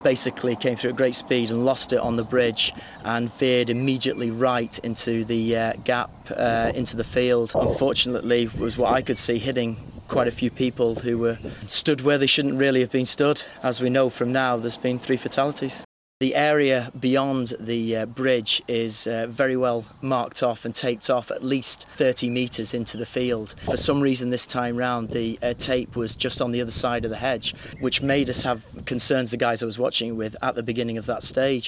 Eyewitness - Jim Clark Rally Crash